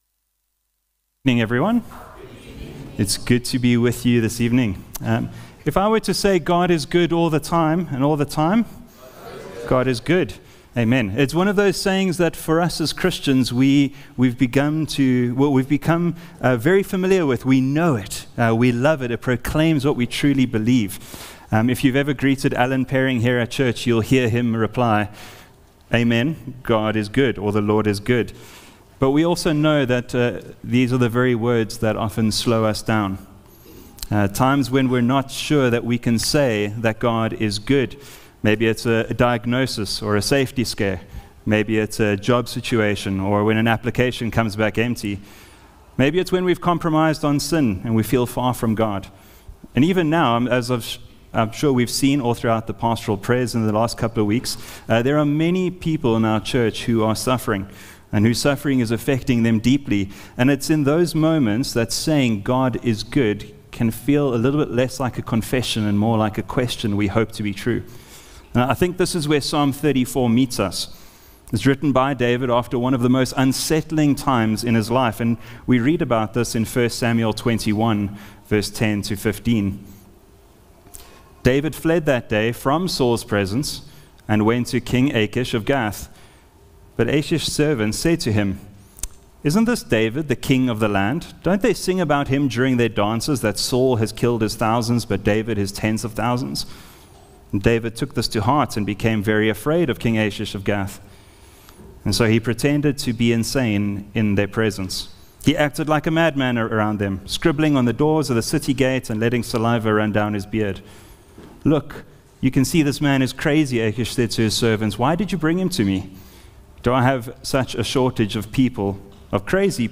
Sermons | Honeyridge Baptist Church